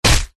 Звуки пощечины
Сильный удар по лицу или телу шлепок хруст 3